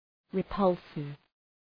{rı’pʌlsıv}